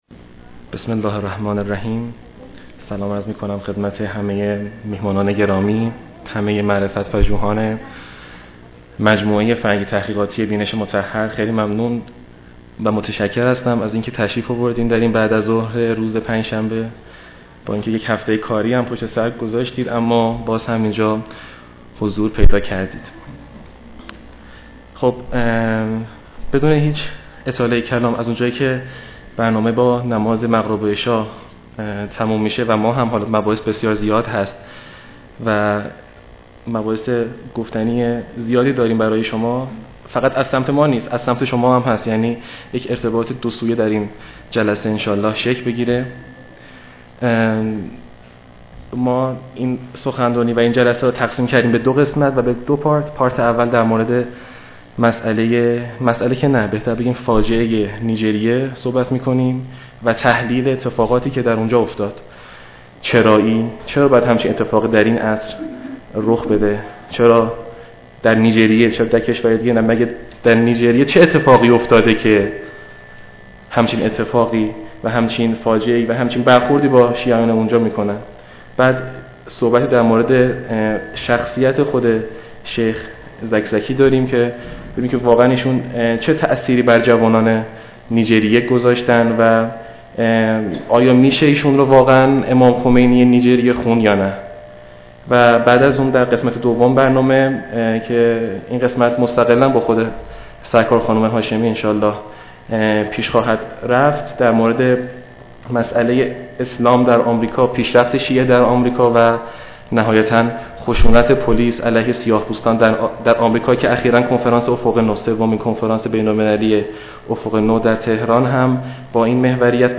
فایل صوتی ازانقلاب امام خمینی درنیجریه چه می دانیم؟ویژه برنامه ای که درسالن همایشهای خانه بیداری اسلامی اصفهان وباسخنرانی خانم مرضیه هاشمی فعال رسانه ای بین المللی ومجری شبکه پرس تیوی ودیگرفعالان فرهنگی نیجریه اجراشد.